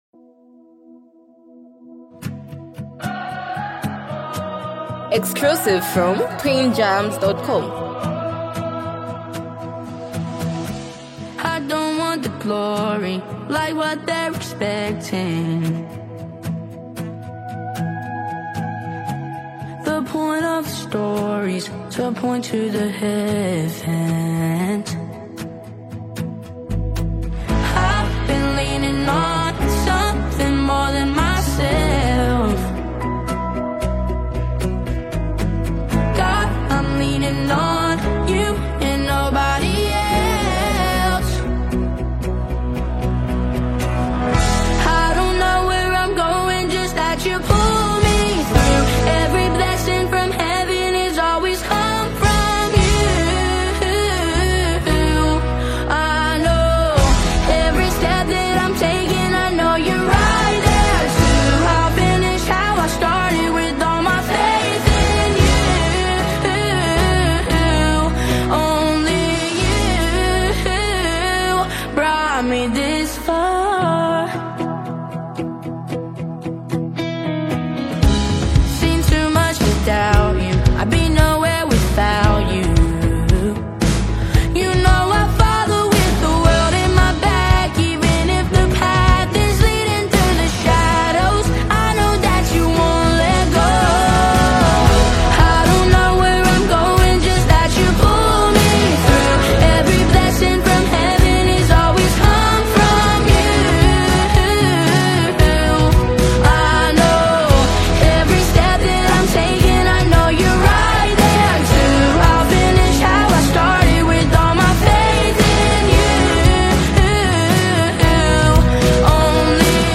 heartfelt and uplifting track